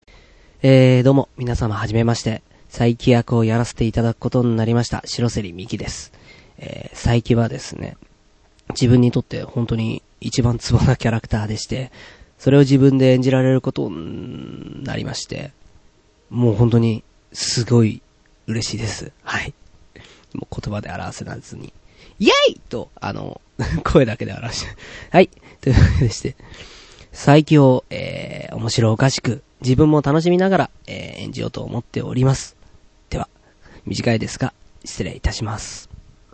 性別：男
サンプルボイス